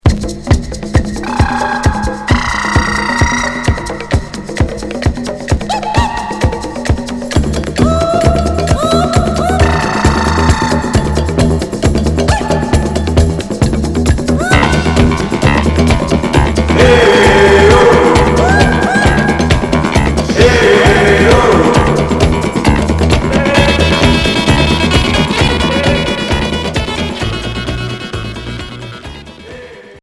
Pop rock Deuxième 45t retour à l'accueil